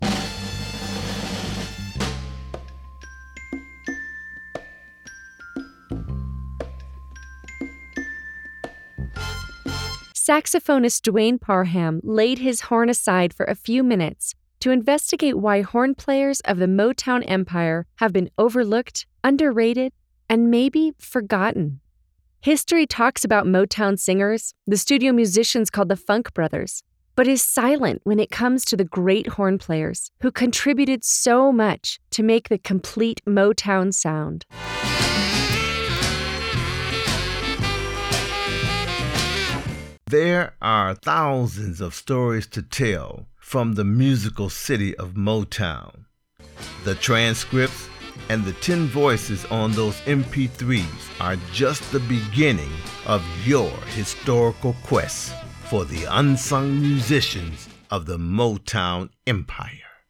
The actual musicians and there personal stories.